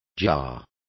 Complete with pronunciation of the translation of jars.